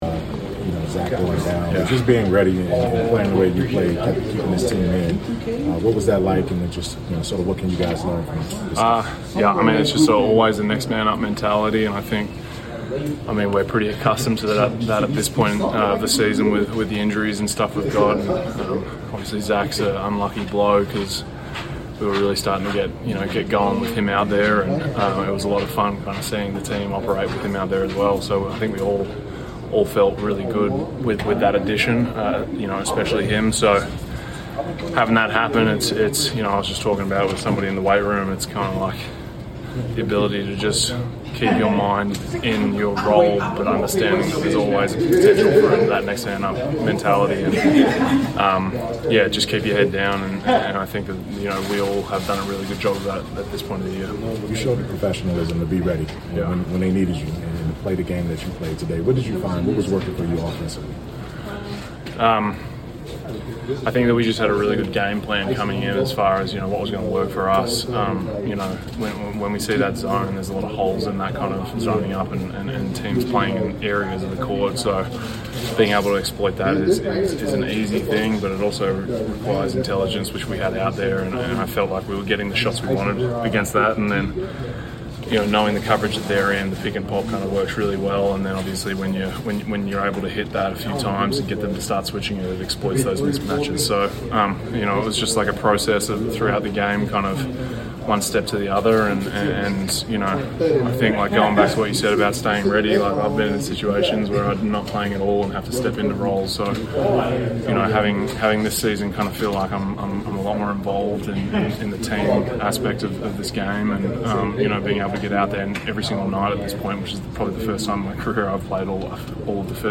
Sonny Cumbie Interview